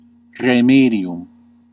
Ääntäminen
US : IPA : /ˈɹɛm.ə.di/